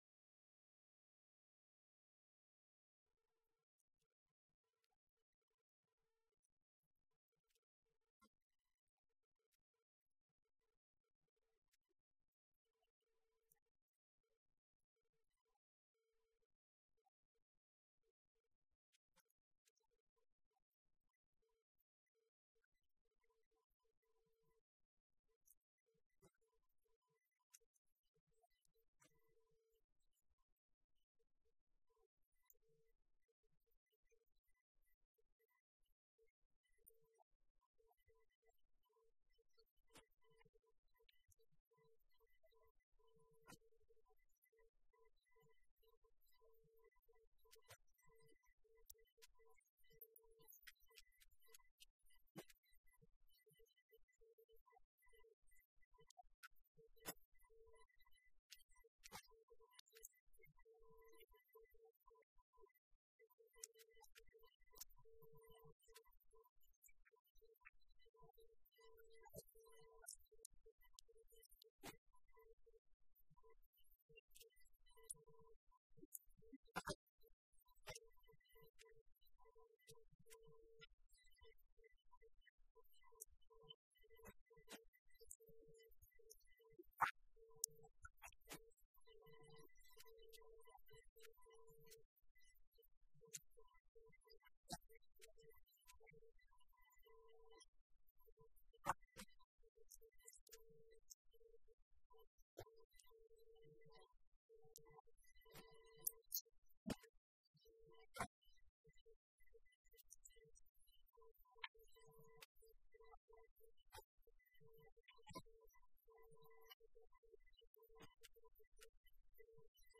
This episode features the second panel discussion of the day at Artemis London 2025, a session focused on the catastrophe bond market segment, from our fourth cat bond and insurance-linked securities (ILS) conference in the City of London, UK, held on September 2nd 2025.